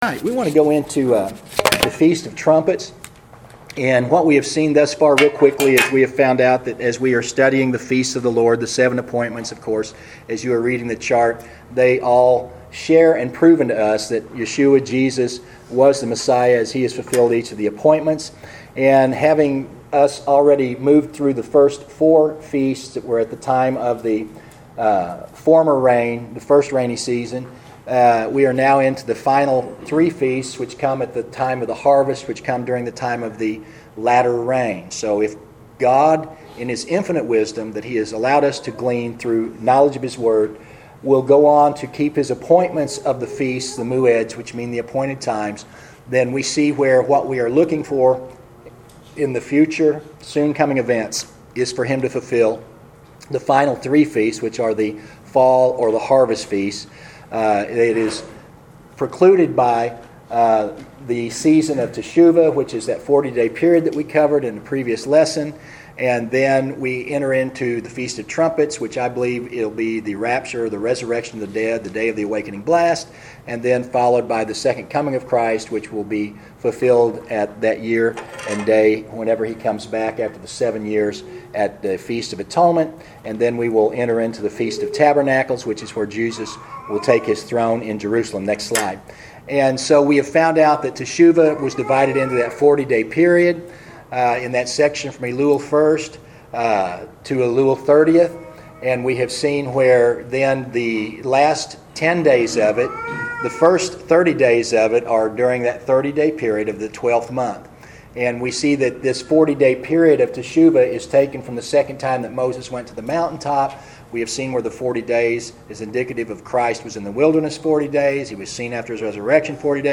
Feast of Trumpets: Audio Lesson Two